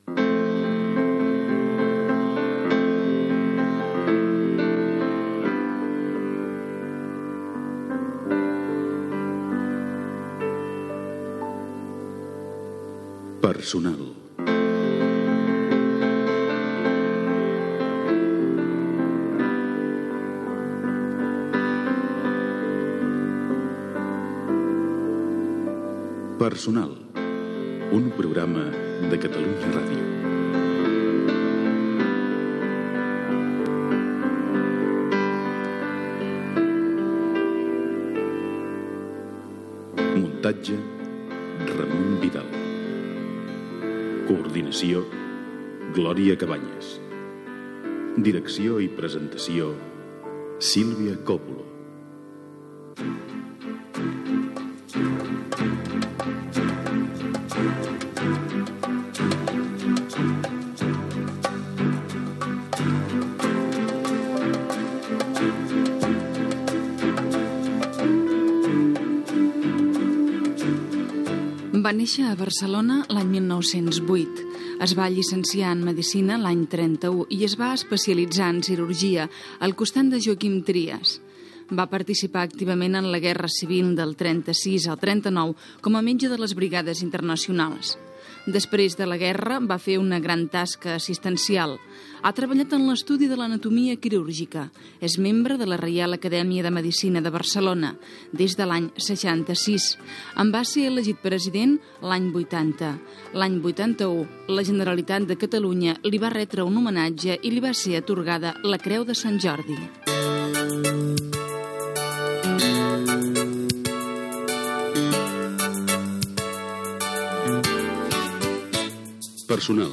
Careta del programa amb els noms de l'equip, presentació biogràfica i entrevista al doctor Moisès Broggi.